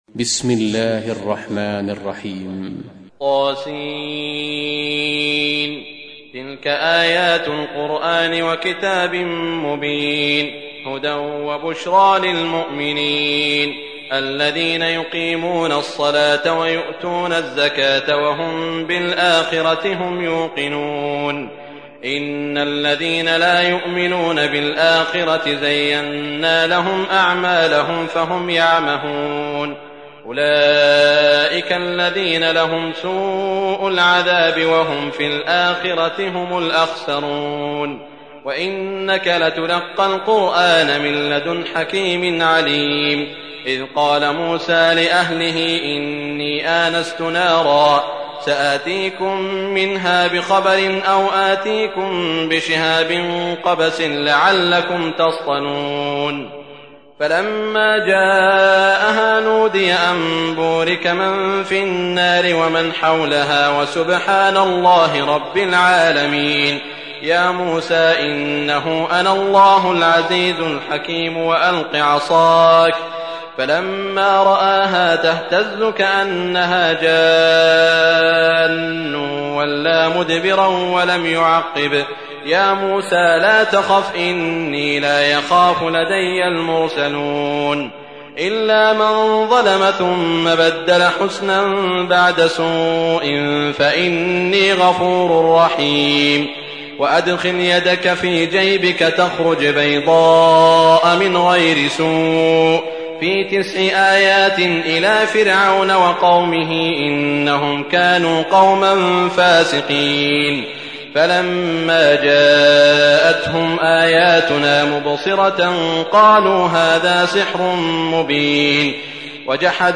سورة النمل | القارئ سعود الشريم